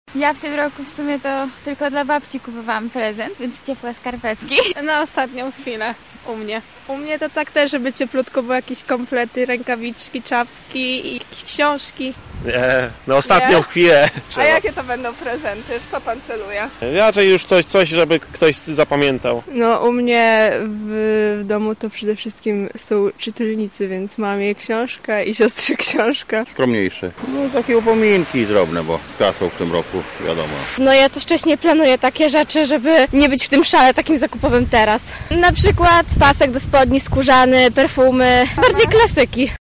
[SONDA] Na jakie prezenty w tym roku stawiają lublinianie?
[SONDA] prezenty